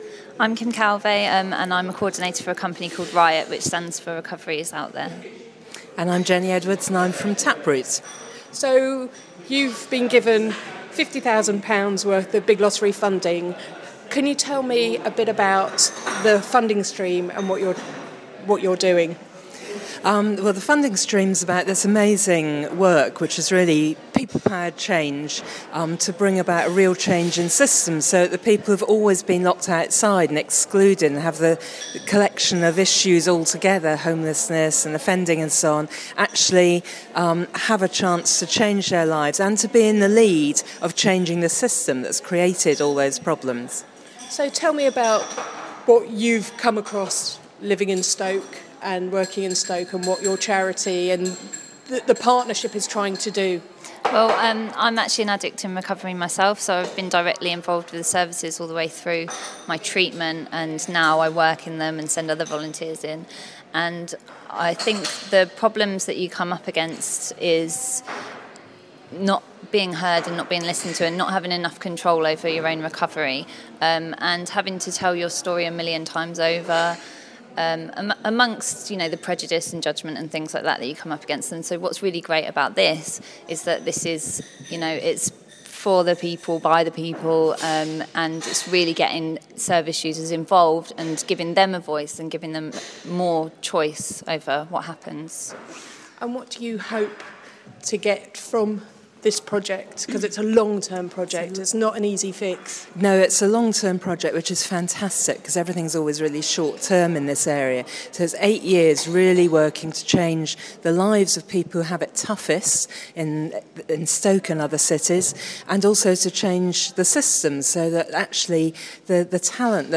The Big Lottery Fund has just launched a £100 million pound fund projects to help people with multiple and complex needs. In this short interview